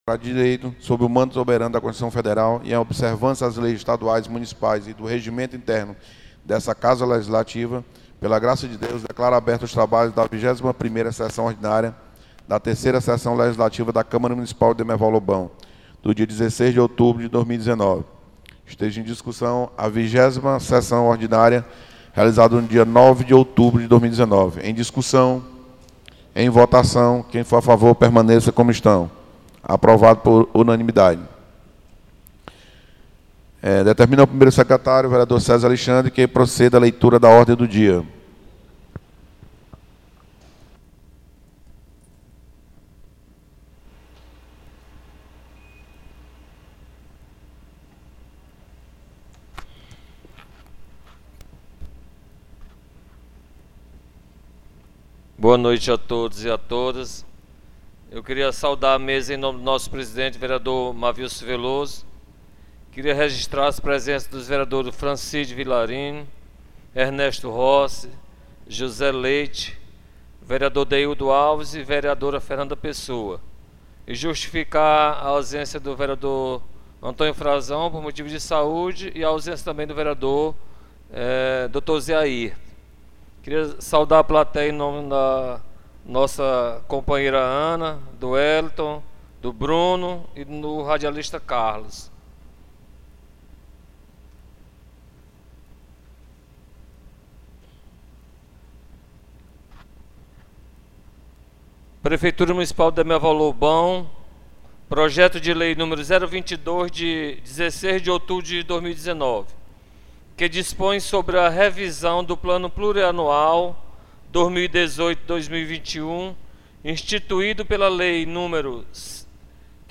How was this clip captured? SESSÕES DA CÂMARA MUNICIPAL